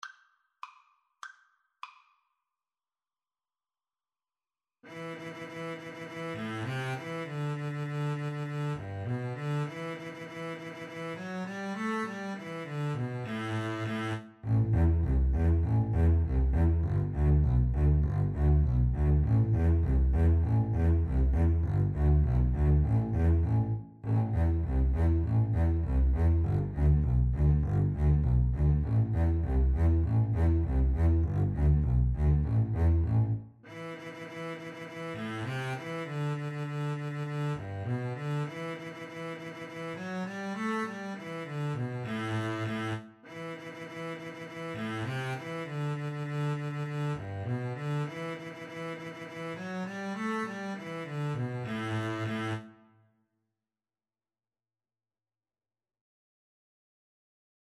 Double Bass Duet version
2/4 (View more 2/4 Music)